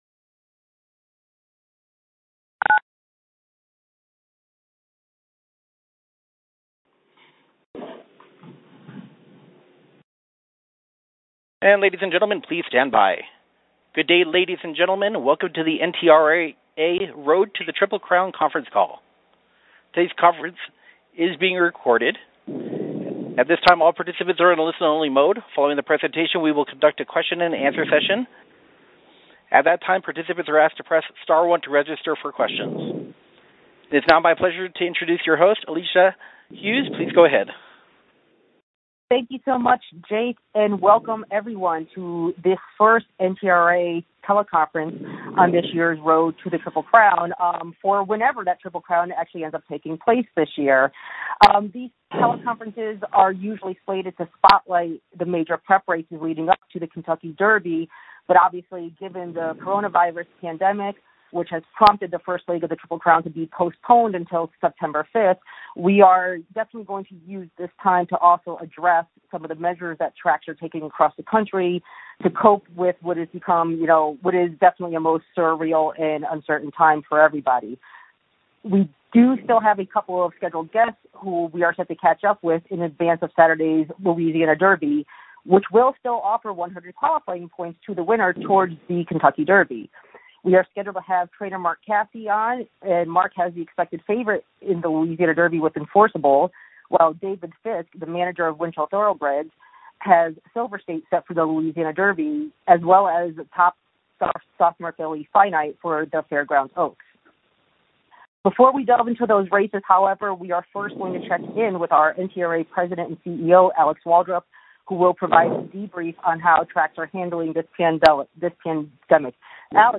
National Media Teleconference